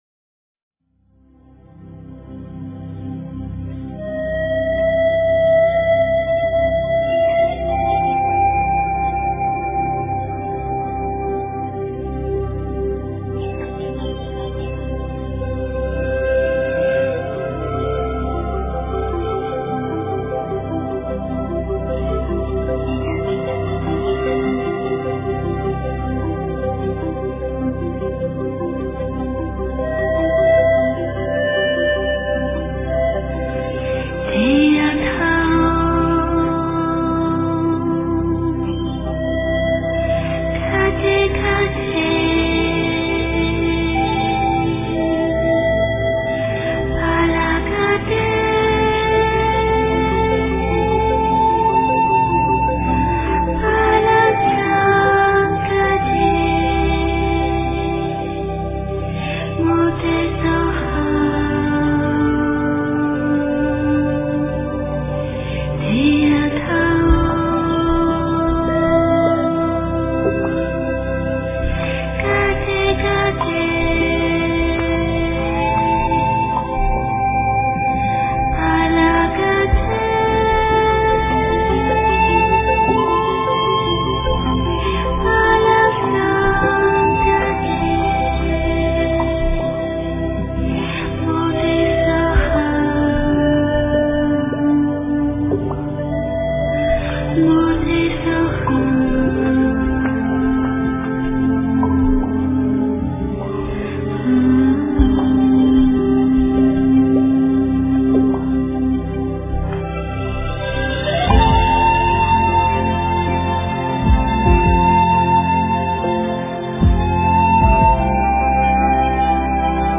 般若佛母心咒--佛音佛语 真言 般若佛母心咒--佛音佛语 点我： 标签: 佛音 真言 佛教音乐 返回列表 上一篇： 大悲咒-藏音--梵音佛语 下一篇： 六字大明咒--梵音佛语 相关文章 药师佛心咒--菩提里的向日葵 药师佛心咒--菩提里的向日葵...